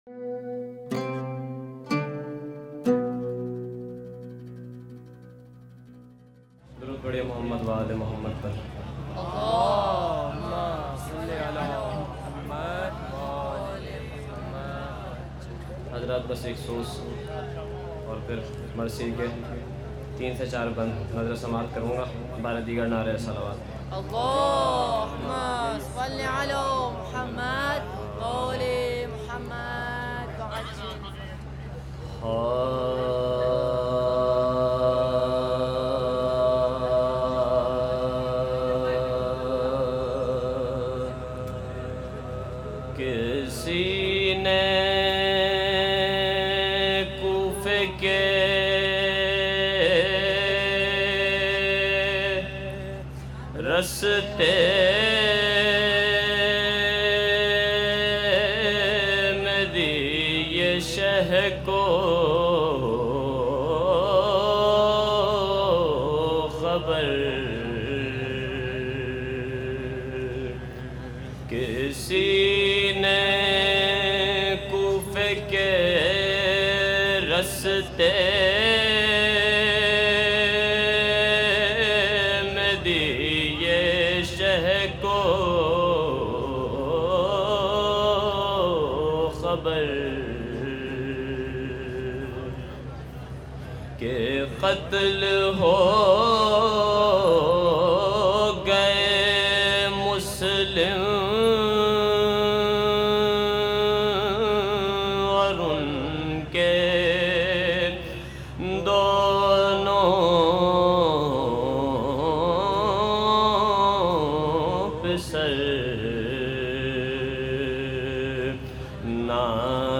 جمع خوانی | اجتماع اردو زبان ها
مراسم جمع خوانی در اجتماع اردو زبان ها | عمود 270 - اربعین سال 1397 | موکب خیمه گاه حسینی